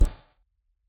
Exhaust.mp3